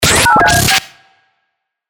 FX-463-PHONE-BREAKER
FX-463-PHONE-BREAKER.mp3